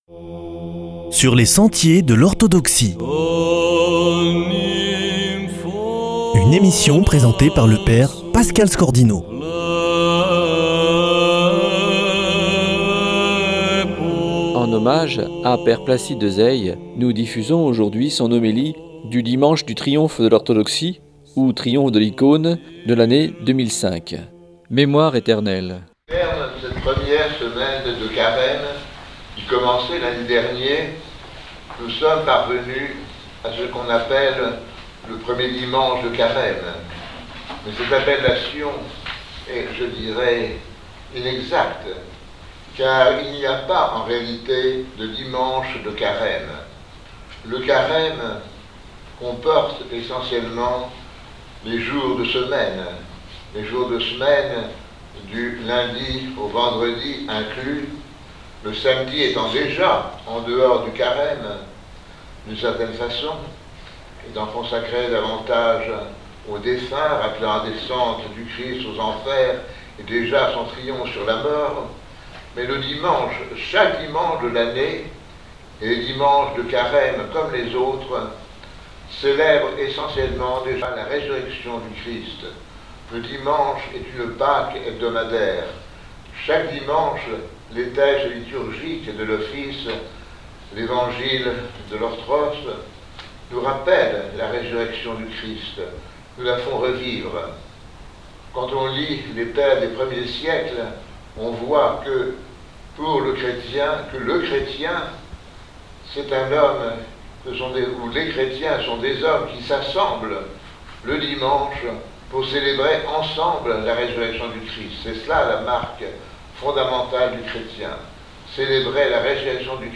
homélie 2005 du dimanche du Triomphe des Icones